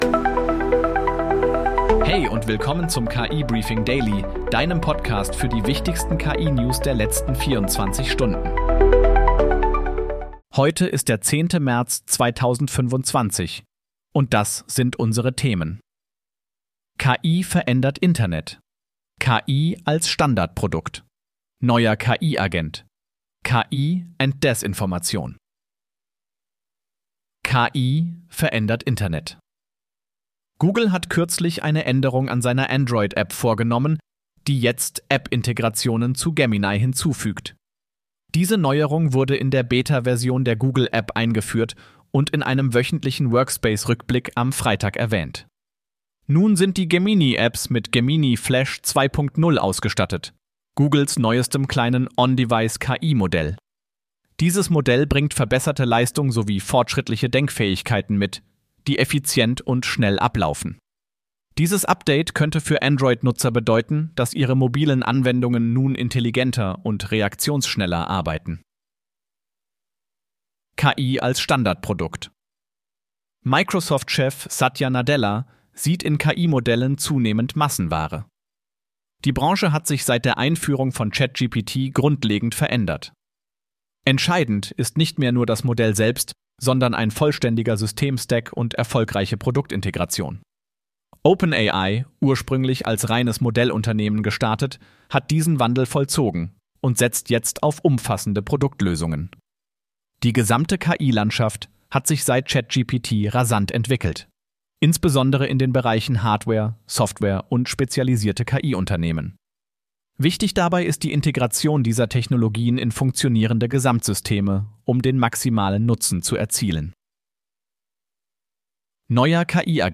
Möchtest du selbst einen solchen KI-generierten und 100% automatisierten Podcast zu deinem Thema haben?